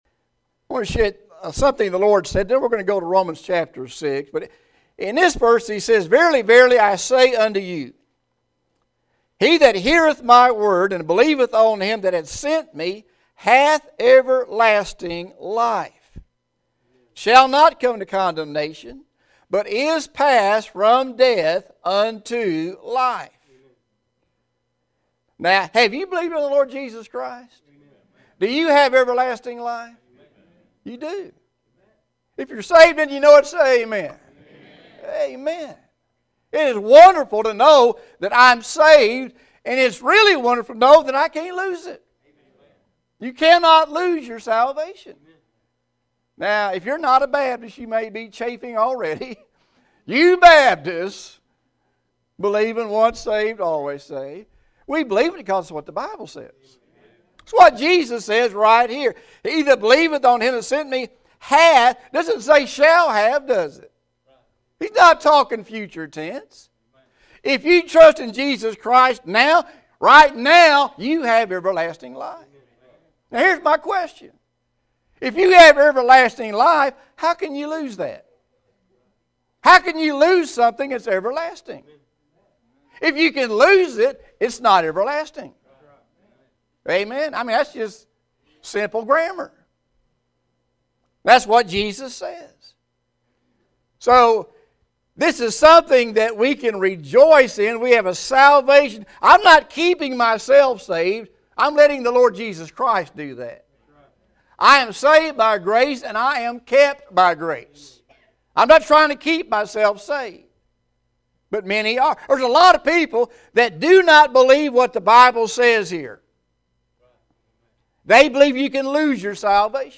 Spring Revival 2018 Night 3